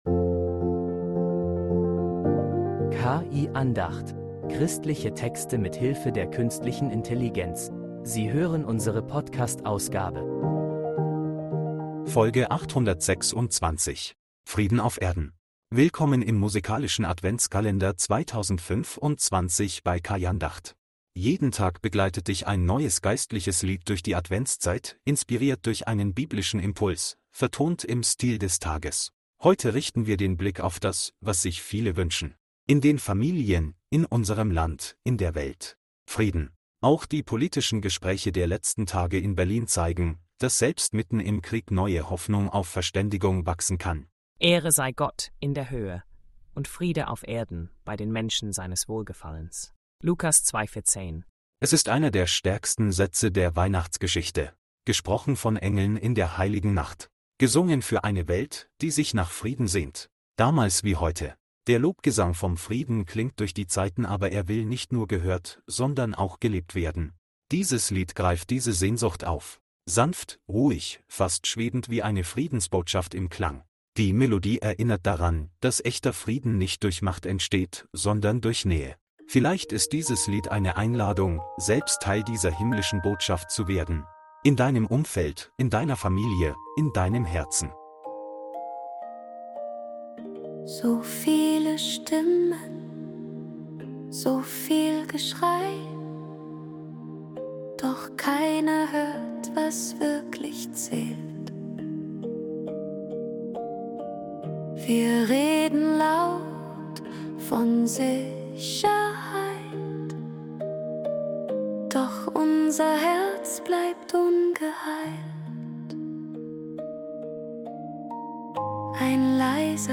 Ein Lied vom Frieden – leise, sanft und voller Hoffnung